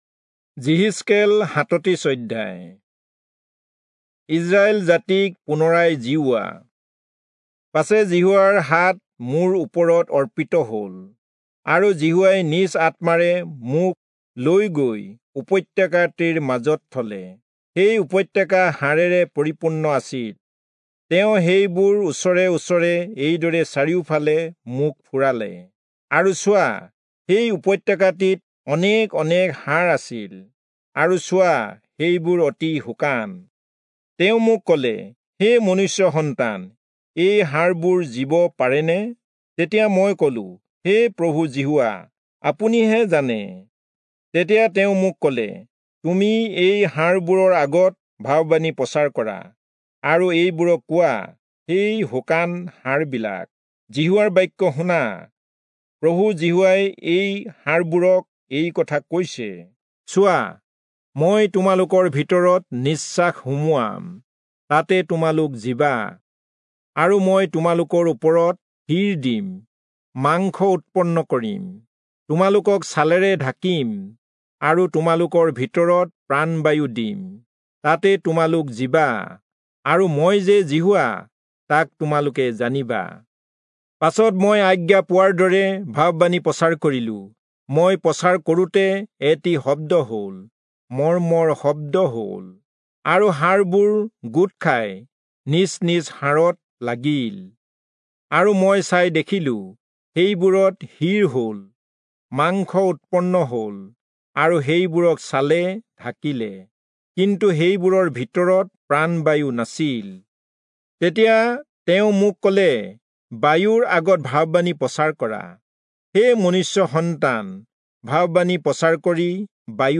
Assamese Audio Bible - Ezekiel 19 in Web bible version